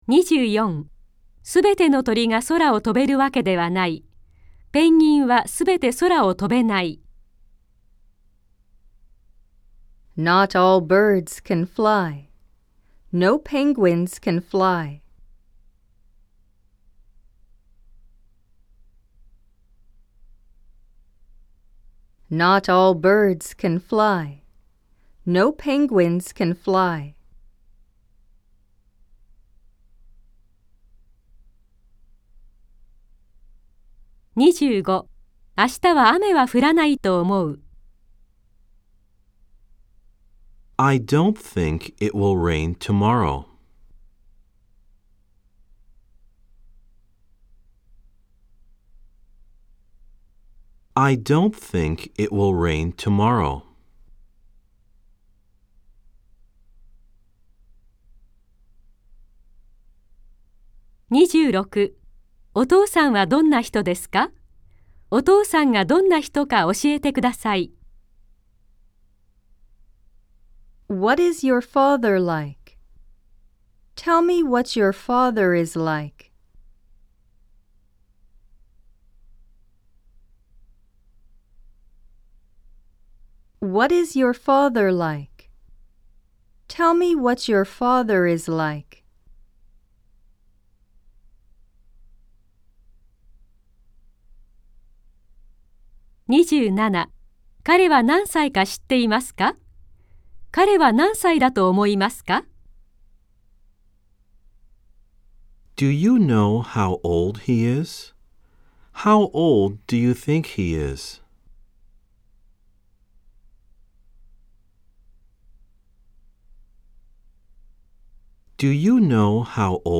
（4）暗唱例文100　各章別ファイル（日本文＋英文2回読み）
※（1）（2）では英文のあとに各5秒のポーズ、（3）（4）では各7秒のポーズが入っています。